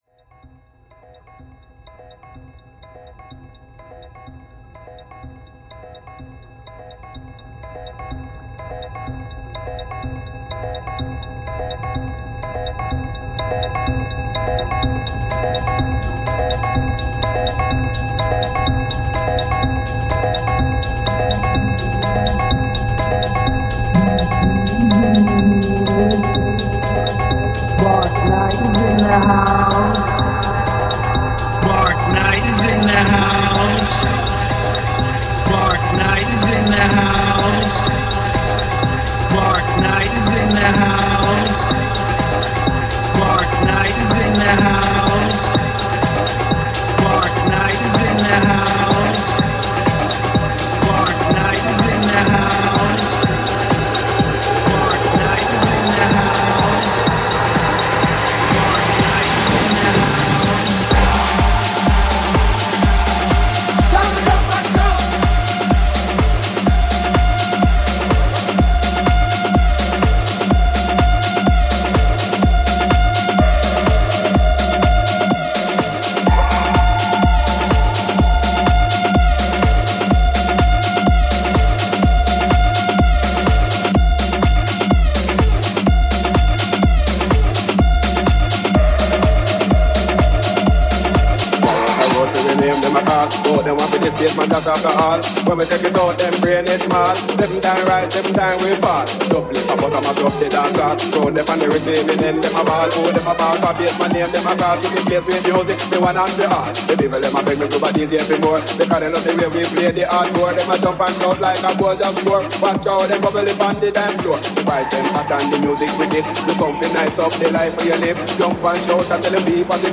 DJ Mix & Live Set
genre: House